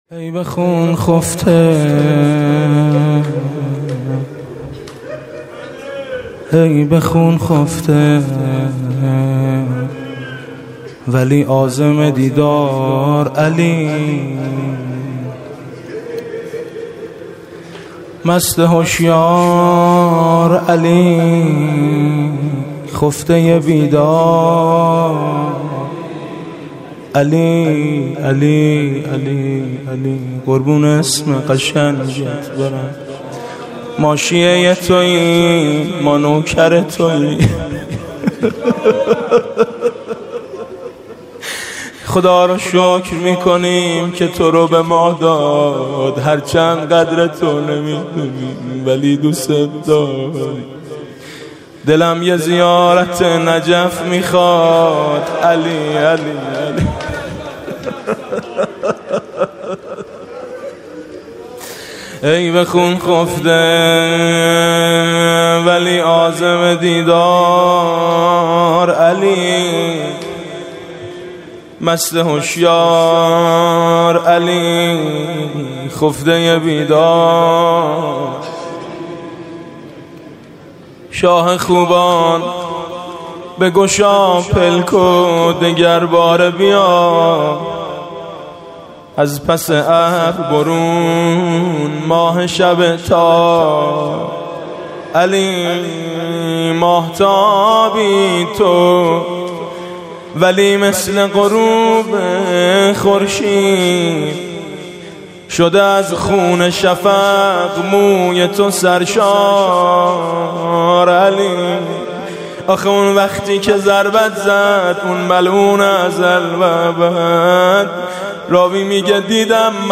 مناسبت : شب بیستم رمضان
مداح : میثم مطیعی قالب : روضه